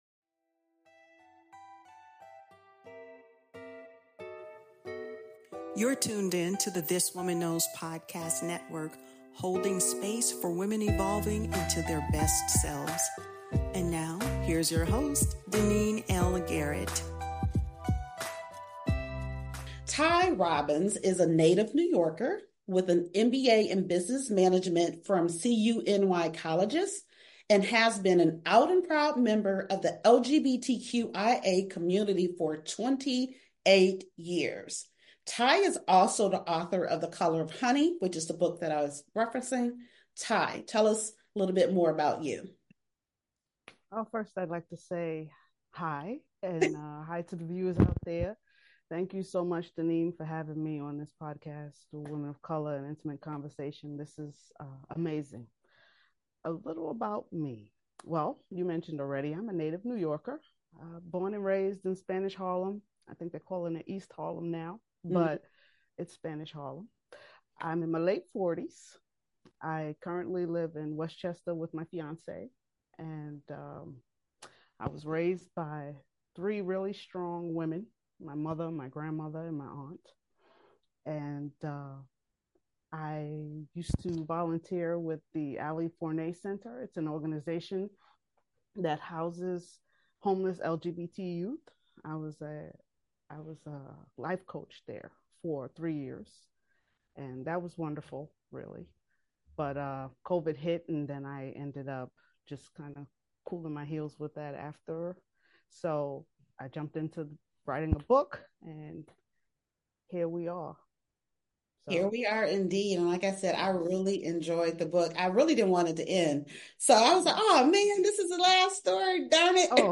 A heartfelt conversation on reclaiming narratives, representation, and the universal power of love, courage, and connection.